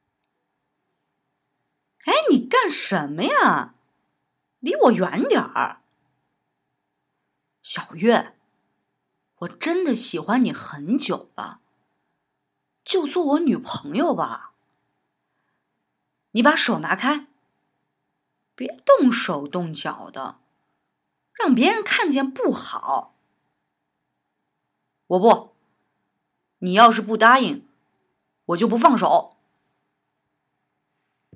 Beispieldialog 2  对话